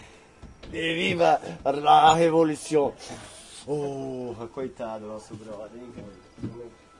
在巴西Guaruja的PraiaBranca录制。
Tag: 全球村 现场记录 mzr50 语音 聊天 ecm907 语音